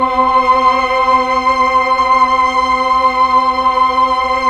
Index of /90_sSampleCDs/Roland LCDP09 Keys of the 60s and 70s 1/PAD_Melo.Str+Vox/PAD_Tron Str+Vox